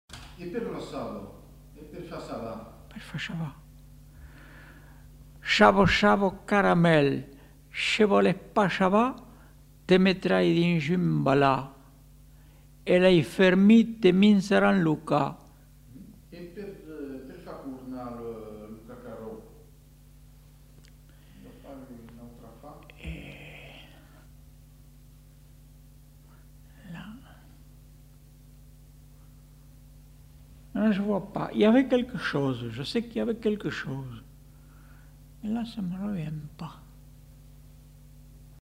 Aire culturelle : Périgord
Lieu : Lolme
Effectif : 1
Type de voix : voix d'homme
Production du son : récité
Classification : formulette